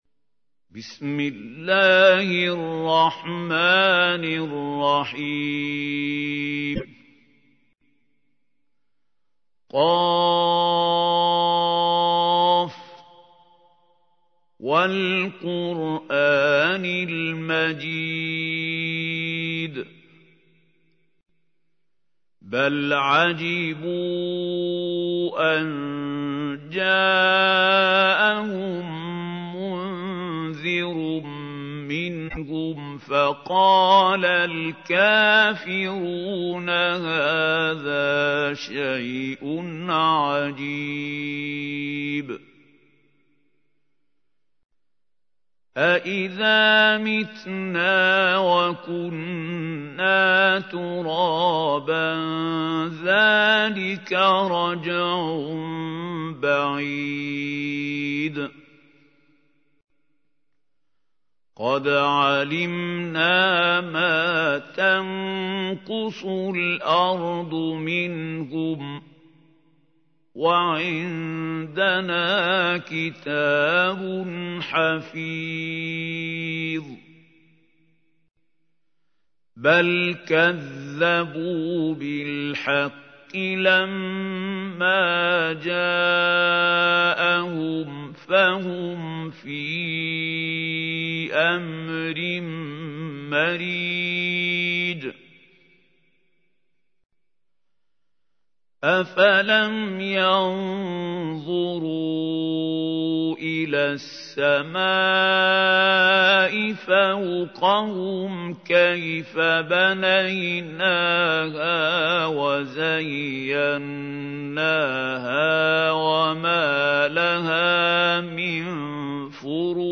تحميل : 50. سورة ق / القارئ محمود خليل الحصري / القرآن الكريم / موقع يا حسين